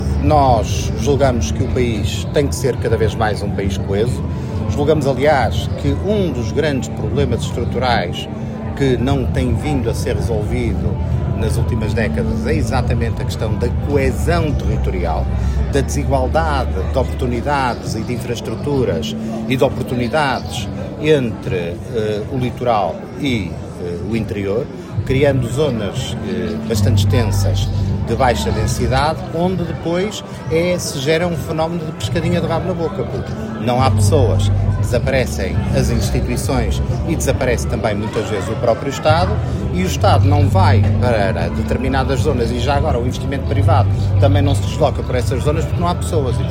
O Ministro dos Assuntos Parlamentares, Carlos Abreu Amorim, visitou, a 18 de abril, a Feira Medieval de Torre de Moncorvo, em representação do Governo, reforçando a atenção do Executivo às problemáticas do interior, numa altura em que a região enfrenta prejuízos significativos provocados pelo mau tempo.
Durante a visita, o governante sublinhou a necessidade de reforçar a coesão territorial, apontando as desigualdades entre o litoral e o interior como um problema estrutural persistente: